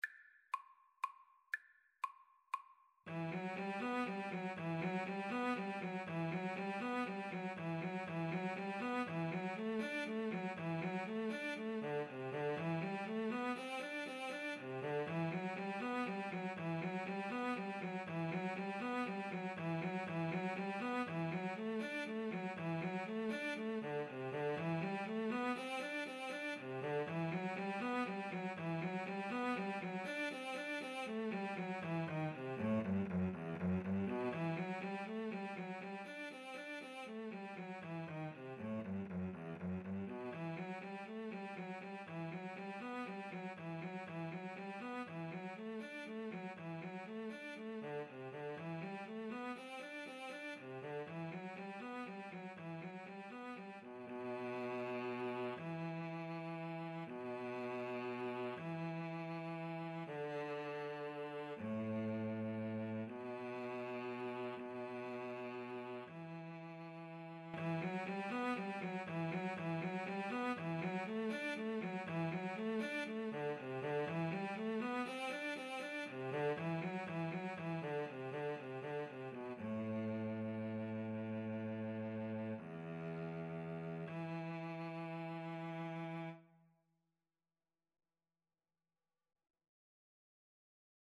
E minor (Sounding Pitch) (View more E minor Music for Violin-Cello Duet )
3/4 (View more 3/4 Music)
Andante (one in a bar) = 120
Classical (View more Classical Violin-Cello Duet Music)